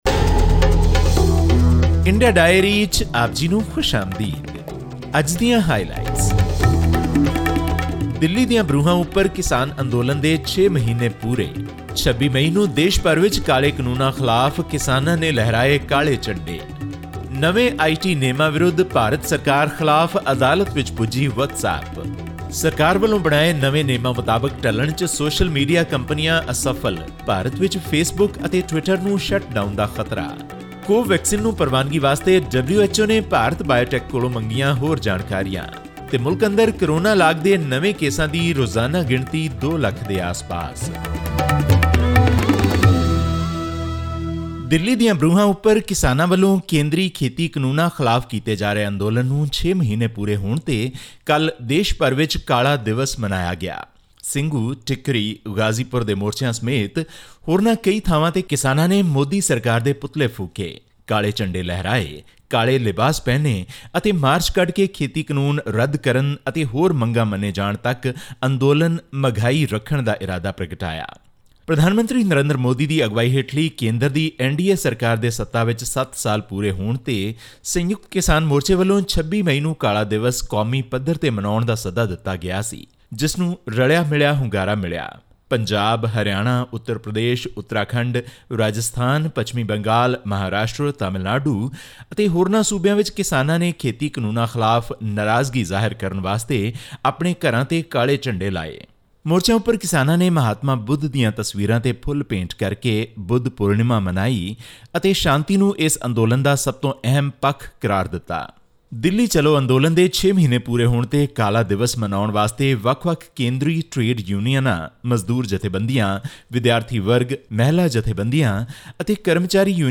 The Facebook-owned messaging app has taken the Indian government to court over new digital rules which could potentially break the end-to-end encryption and violate the privacy of users. All this and more in our weekly news segment from India.